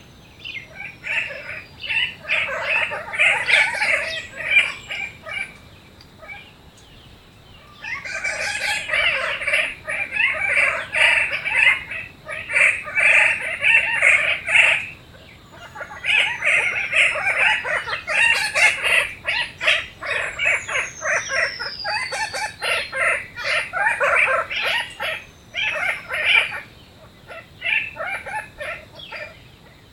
Norfolk Parakeet
Cyanoramphus cookii
Parakeet Norfolk MT PITT NF AUS alternate call [A] ETSJ_LS_71918 (edit).mp3